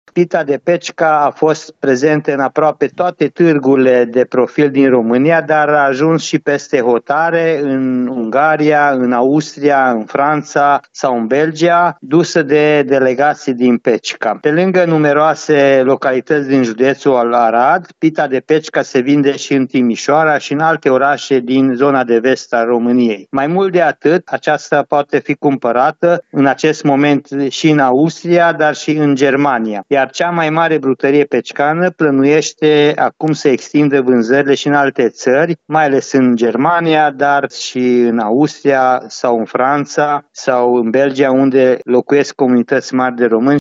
Primarul orașului Pecica, Petru Antal, spune că Pita de Pecica este brand inregistrat la OSIM din 2011 și este produsă in patru brutării din oraș.